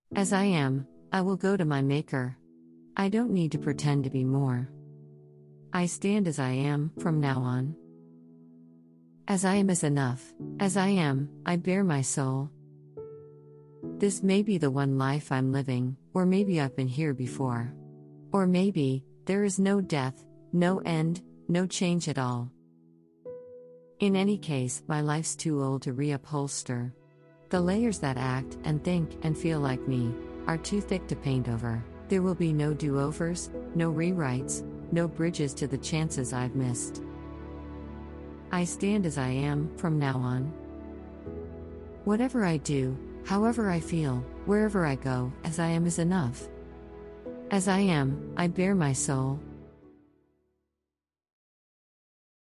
poetry